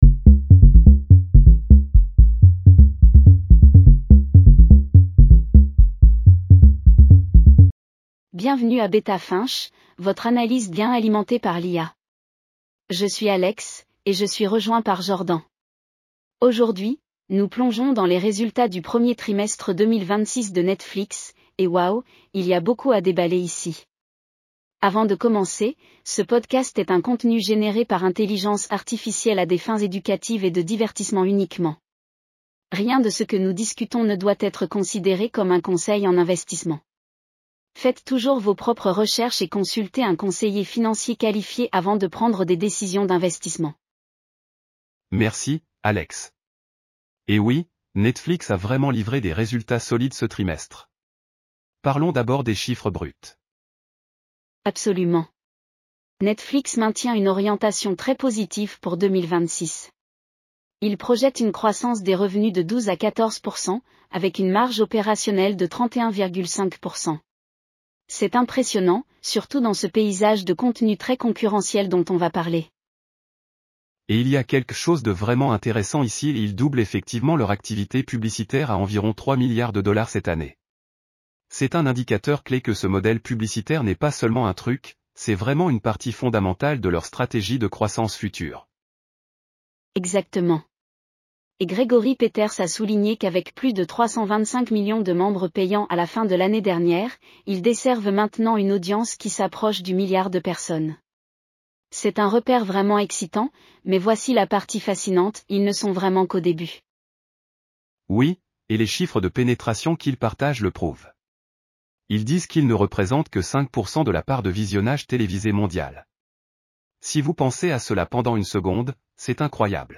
Bienvenue à Beta Finch, votre analyse d'gains alimentée par l'IA.